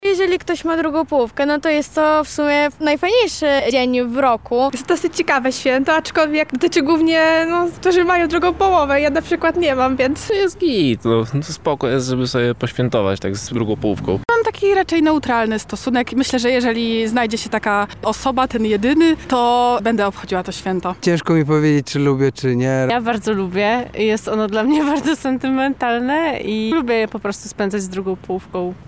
Zapytaliśmy lubelskich studentów, jak postrzegają Walentynki i czy lubią to święto:
SONDA